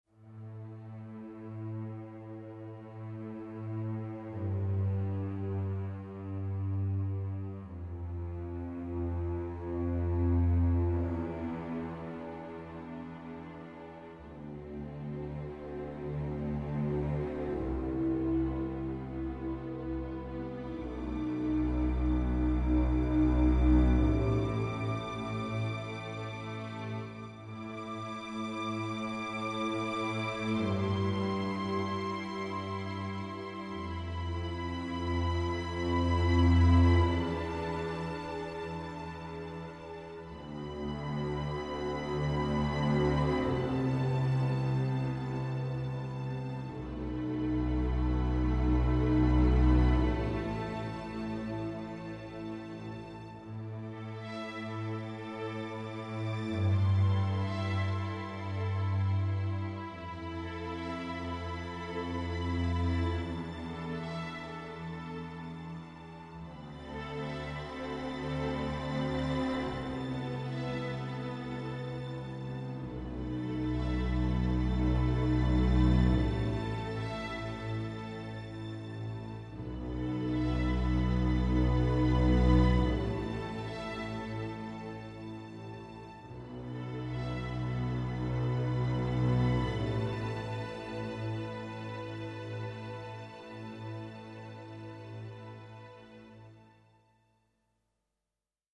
End Titles Strings